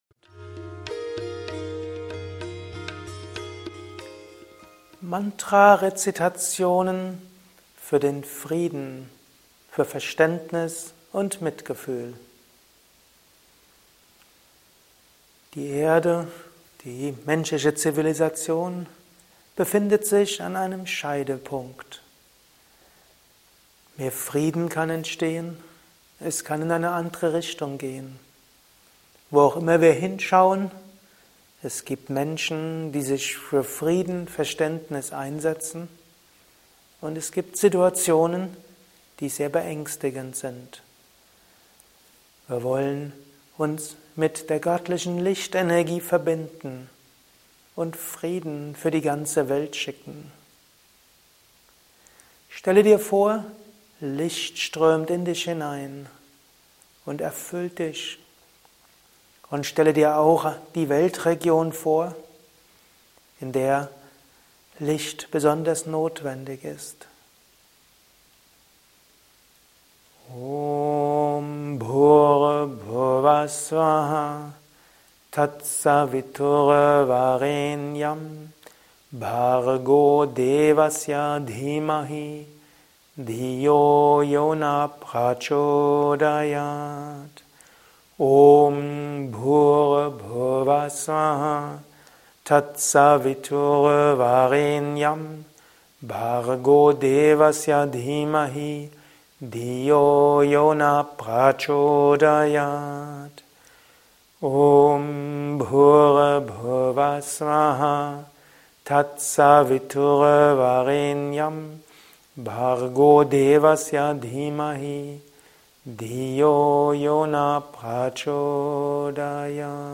Mantra Rezitation für den Frieden, für Verständnis und Mitgefühl ~ Mantra-Meditation Anleitung Podcast
MantraRezitation_Fuer-Frieden-Verstaendnis-und-Mitgefuehl.mp3